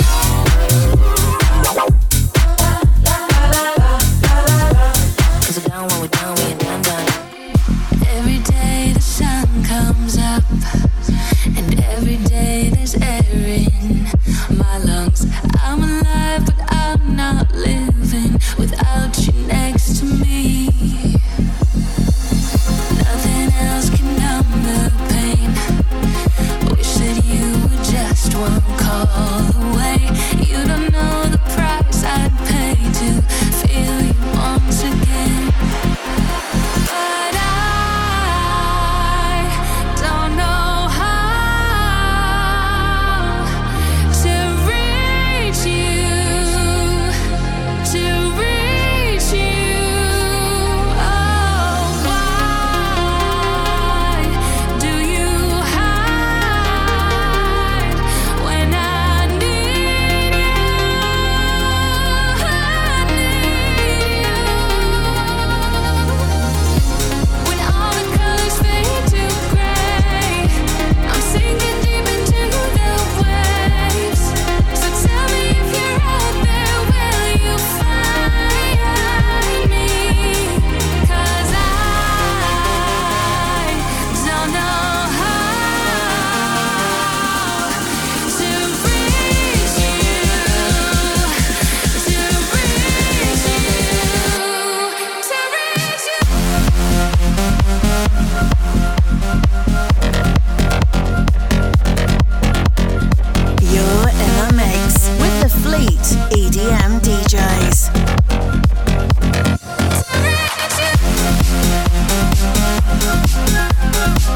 Genre: House, Electronic, Dance.